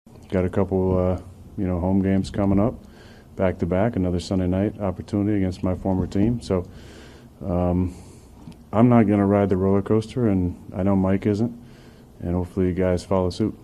Rodgers says the Steelers missed a chance for a win last night, but they’ll be okay.